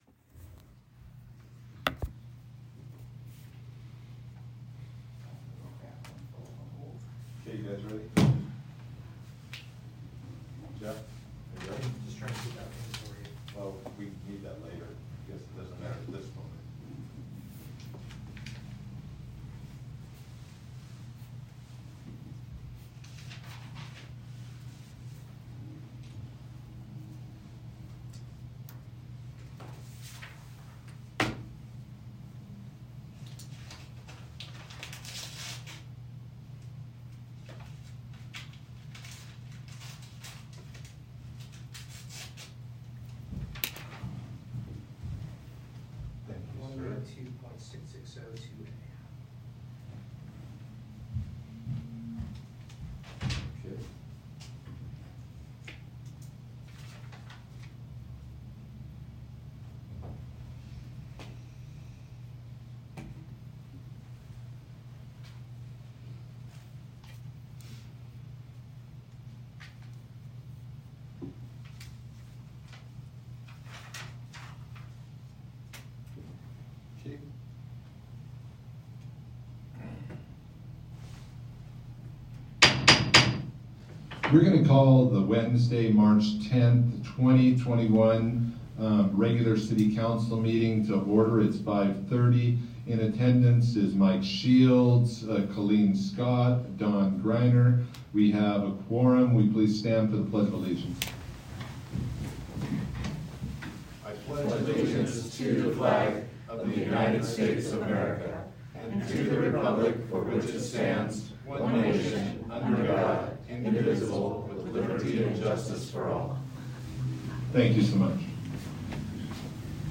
Regular City Council Meeting | City of La Pine Oregon